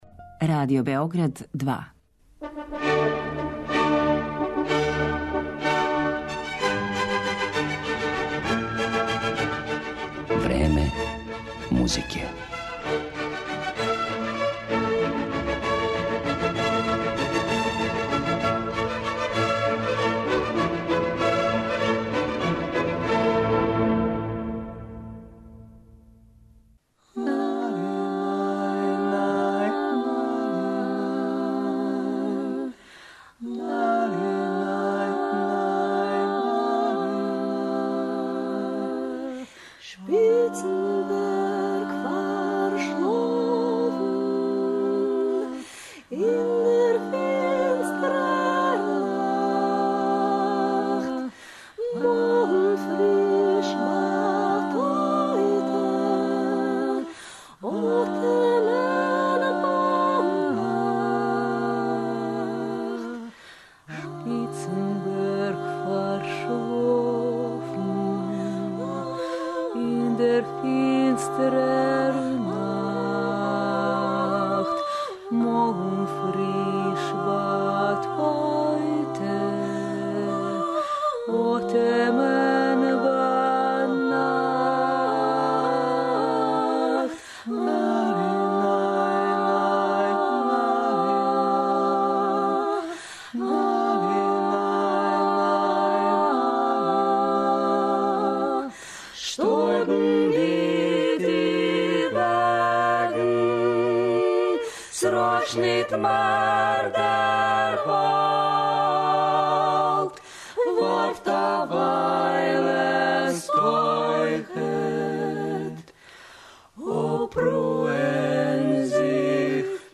Клезмер је некада био, махом, инструментална музика коју су Јевреји широм Централне Европе изводили током својих светковина.
Чињеница да се из ове, некада инструменталне музике, стигло и до а капела стила, можда је најмања од промена које се последњих деценија догађају у овом жанру.